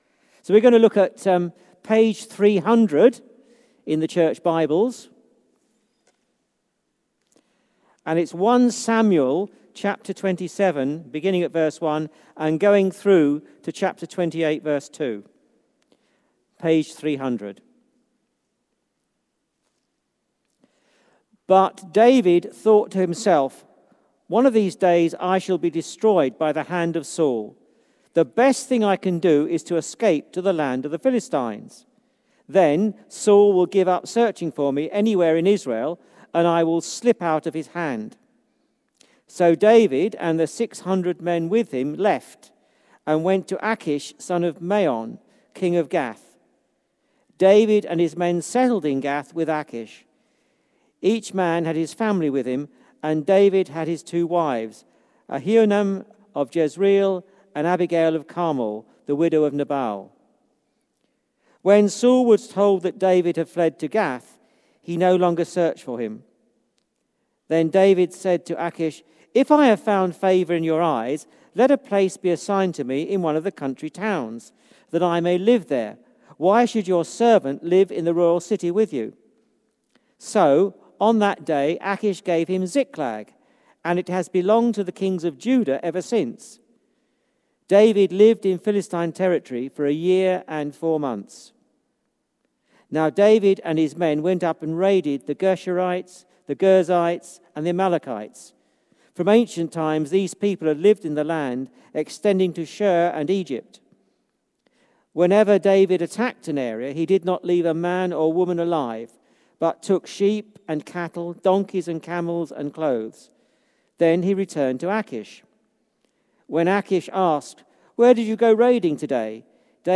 Living Among Enemies: David's Time with the Philistines Sermon